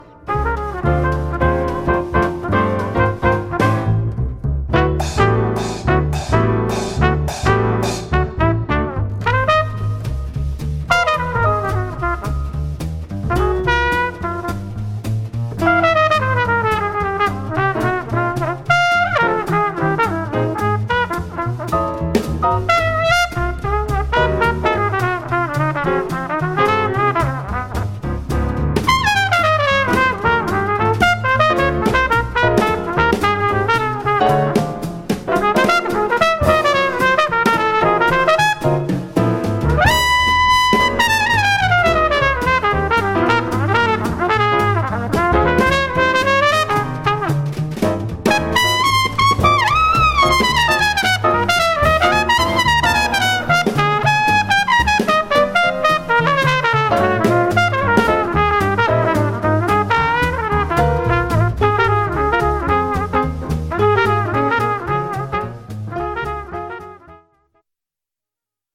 trompette
piano
contrebasse
batterie